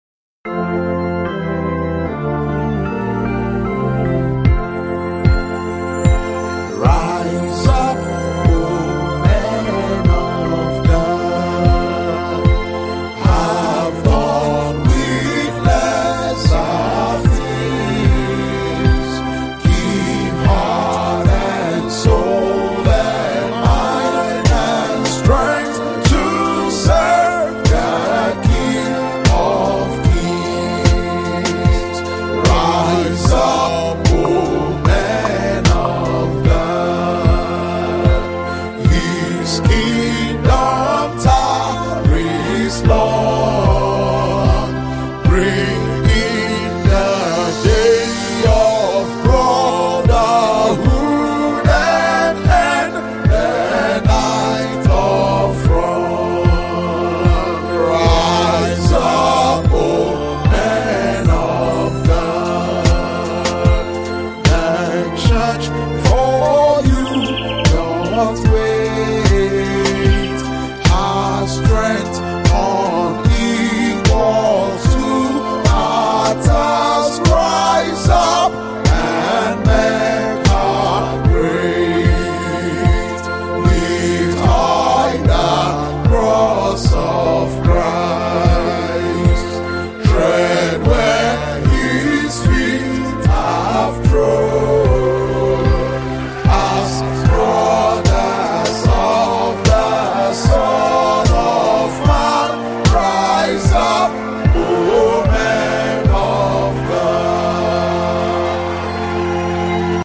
MCA-National-Anthem1.mp3